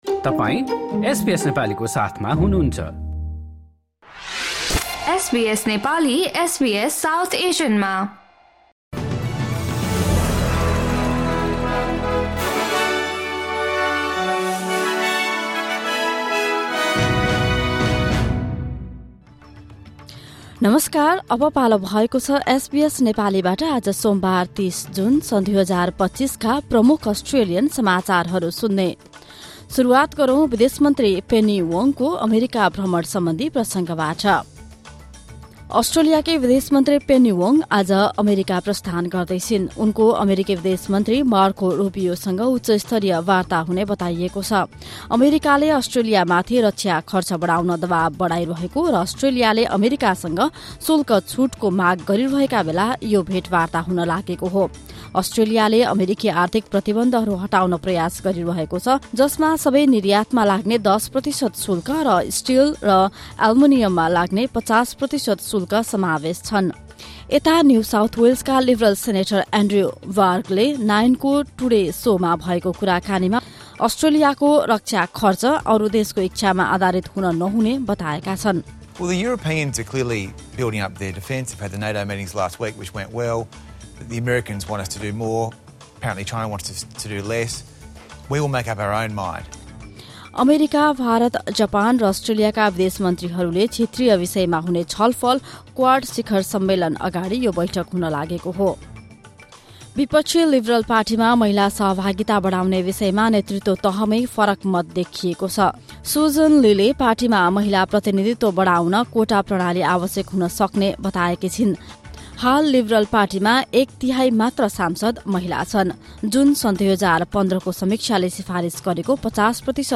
SBS Nepali Australian News Headlines: Monday, 30 June 2025